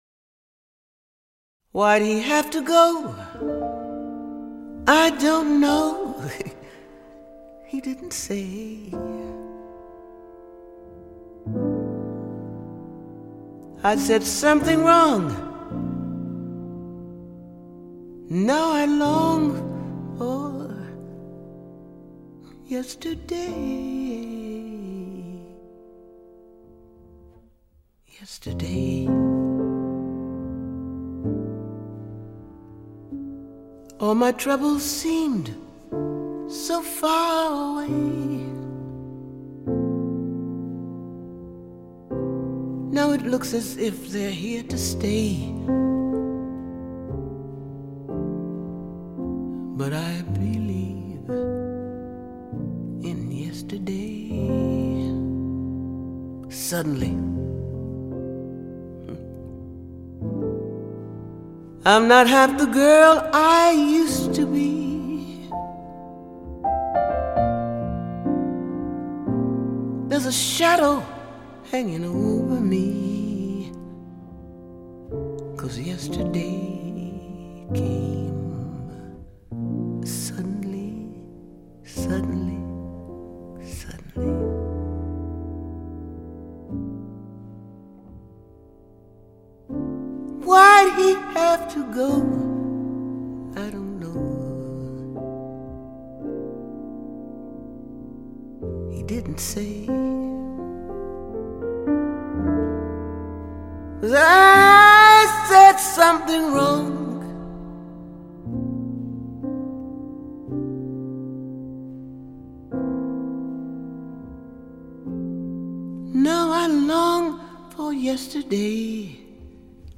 [light]獨特悠閒的音樂空間，36首最能掌握時代脈動的爵士樂精選 !